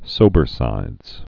(sōbər-sīdz)